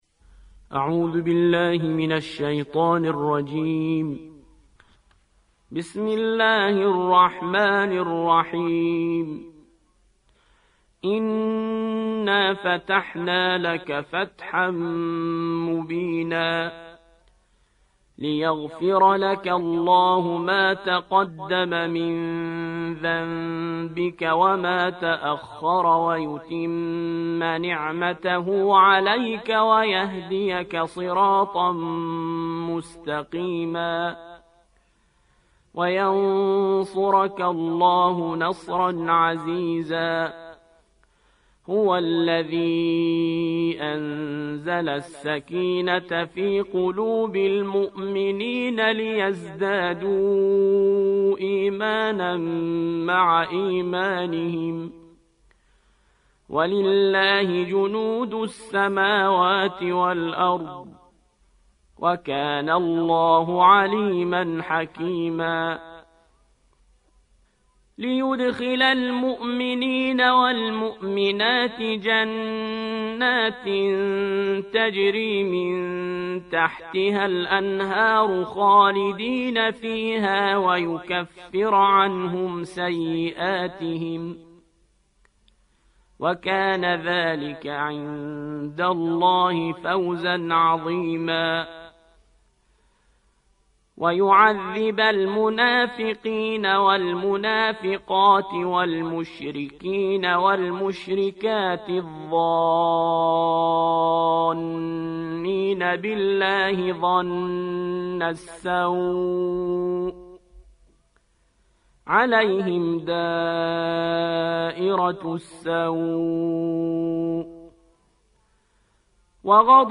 48. سورة الفتح / القارئ